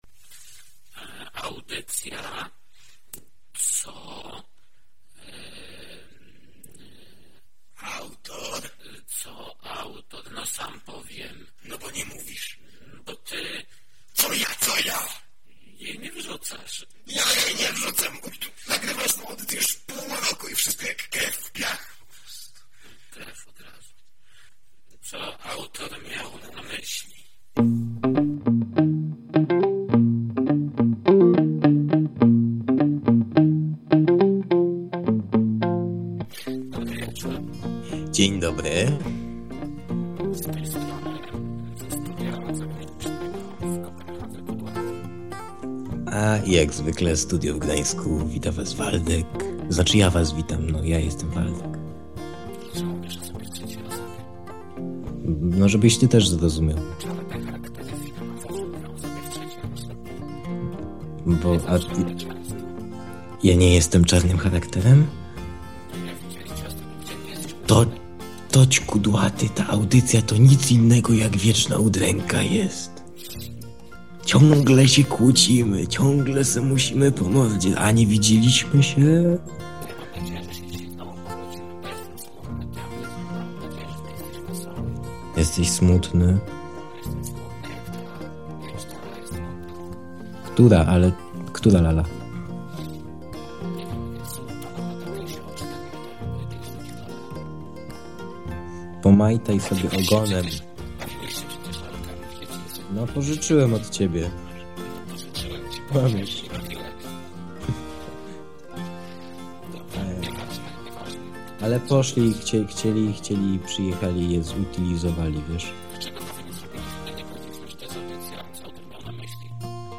Fragment utworu zaczerpnięty z YouTube.
"Co Ałtor Miał na Myśli" to audycja rozrywkowa, nagrywana co tydzień lub dwa.